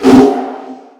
Mafia - Perc 3.wav